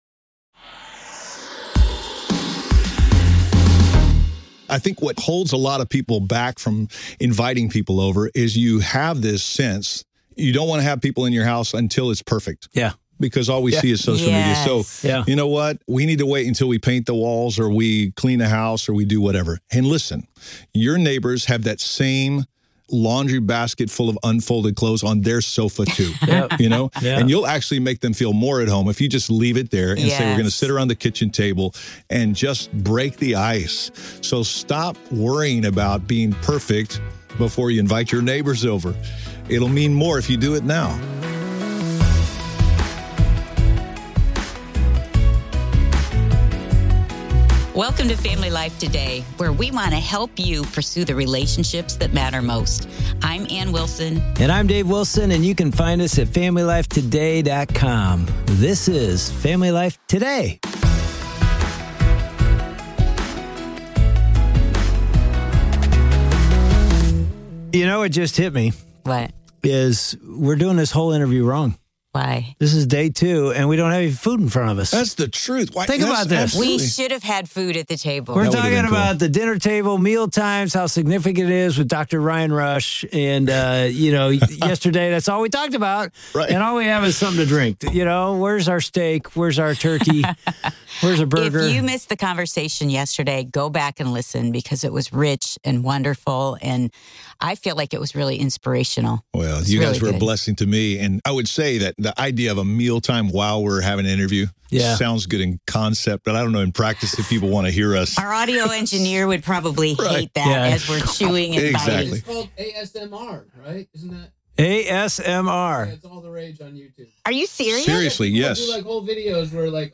The conversation begins with a humorous remark about how the interview feels "wrong" because there's no food present, highlighting the importance of food and mealtimes in their daily lives.